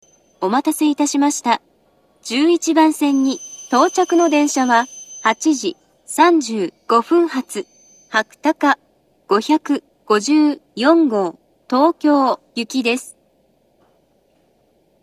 なお、到着放送の鳴動は非常に遅く、駅員放送にかき消されやすいです（当サイトも１２番線は収録できておりません）
１１番線到着放送